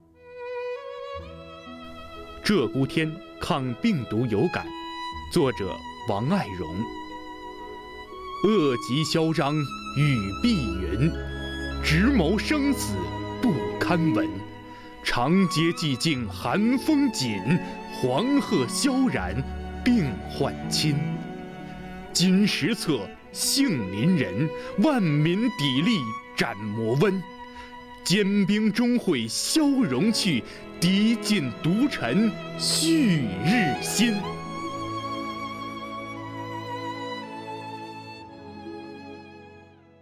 为声援战斗在一线的工作人员，鼓舞全区人民抗击疫情的信心和决心，丰南文化馆、丰南诗歌与朗诵协会继续组织诗歌与诵读工作者、爱好者共同创作录制诵读作品。